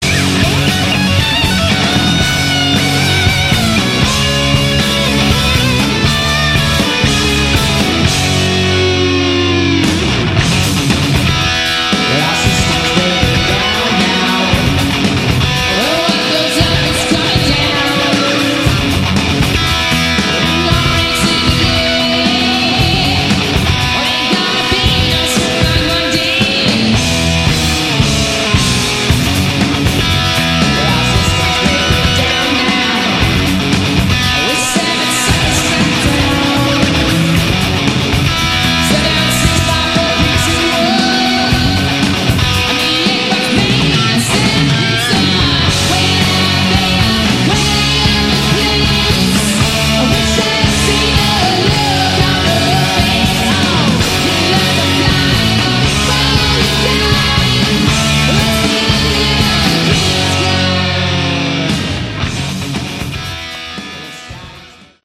Category: Glam/Hard Rock